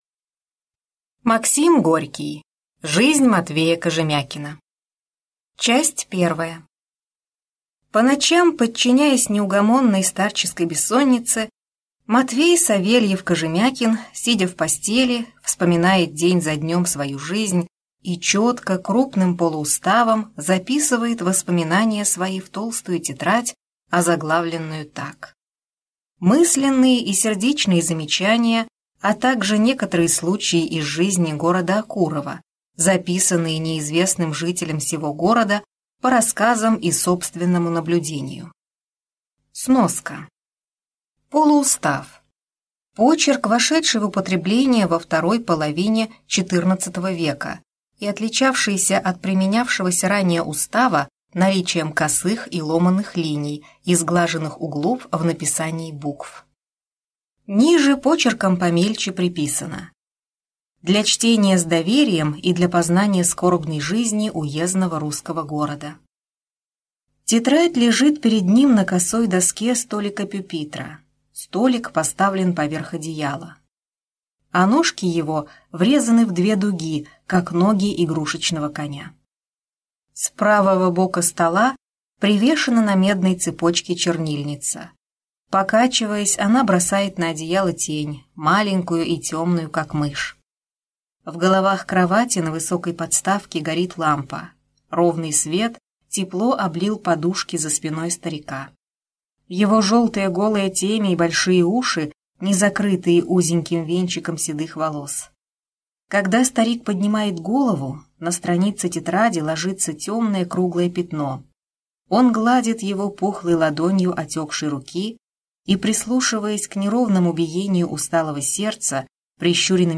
Аудиокнига Жизнь Матвея Кожемякина | Библиотека аудиокниг